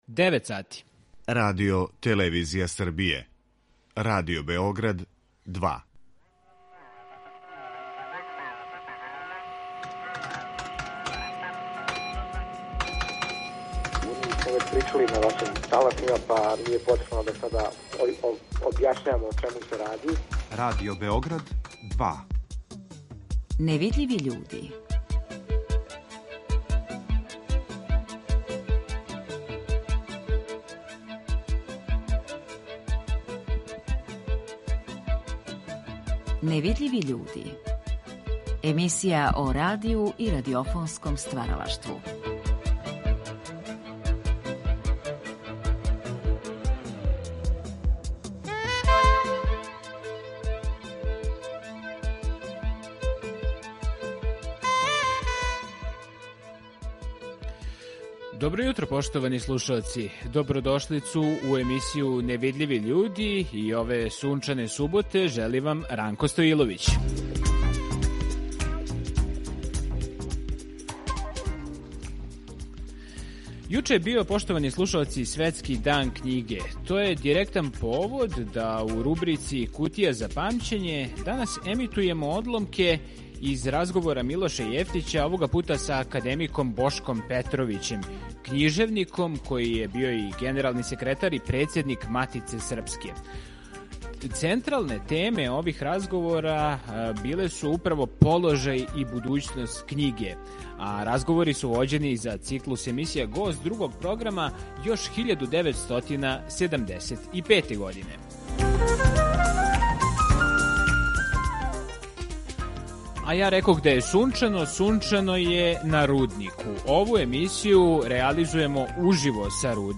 Уживо са Рудника - изложба старих радио и ТВ апарата
Емисију Невидљиви људи ове суботе реализујемо са Рудника, из Дома културе „Војислав Илић", где је у току изложба старих радио и ТВ апарата Рудничко-таковског краја.